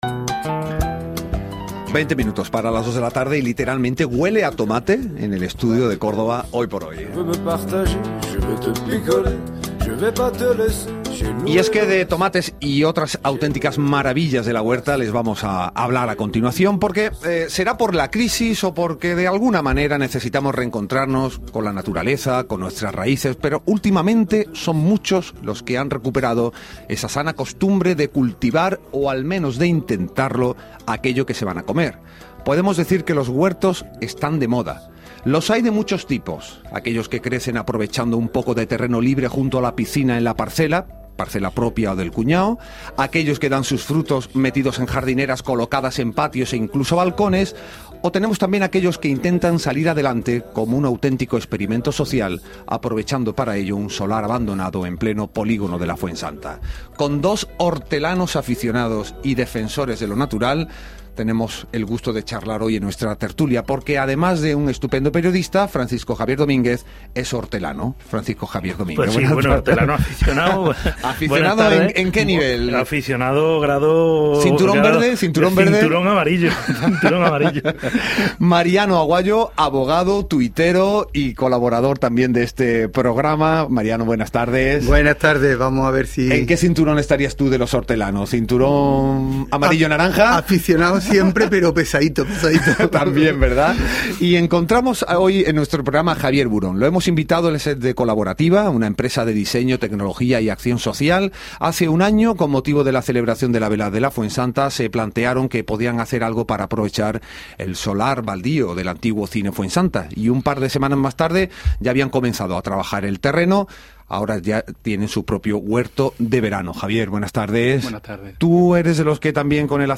2013 Agosto. El huerto urbano Fuensanta en la tertulia de la Ser. Radio Córdoba